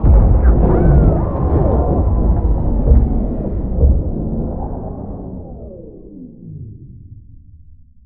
repairstop.wav